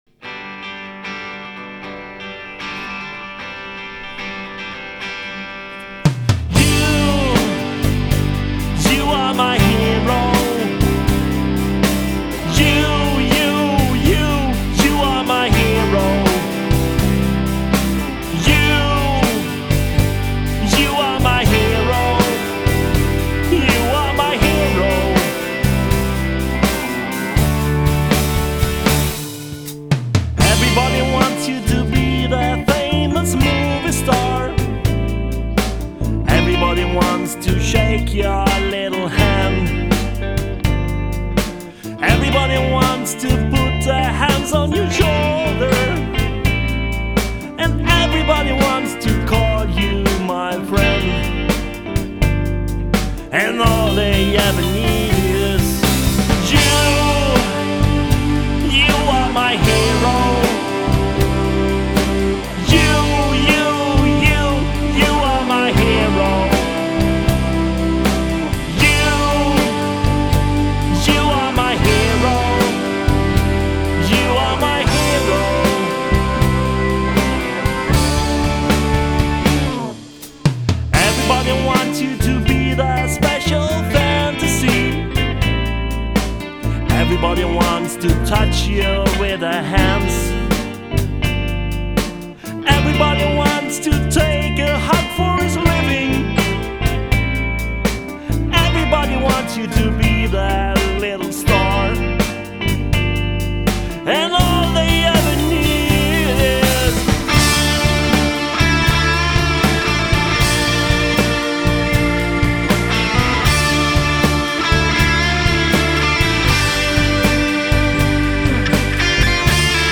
Inspelad på Sveriges Radio Halland.   2003